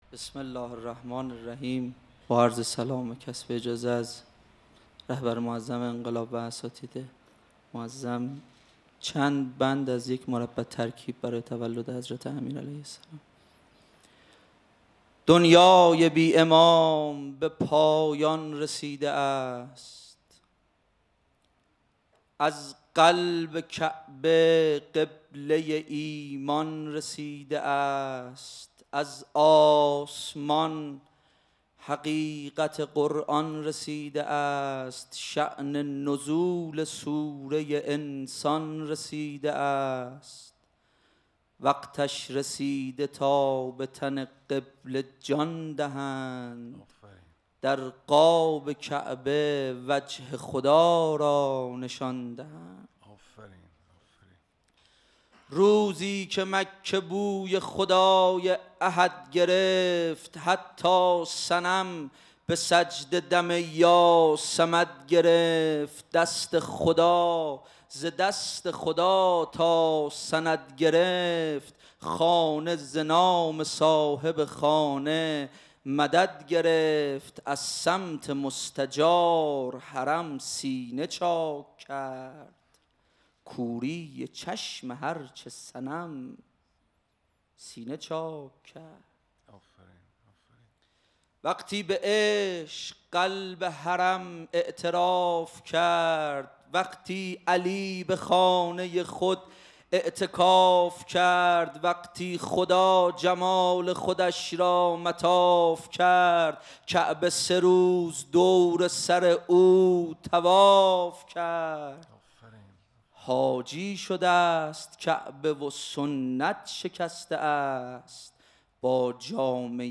شعر خوانی | دنیای بی‌ امام به پایان رسیده است
محفل شاعران آئینی | حسینیه امام خمینی(ره)